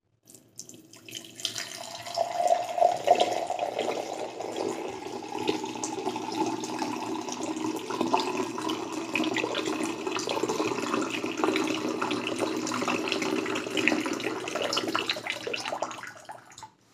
sound-of-water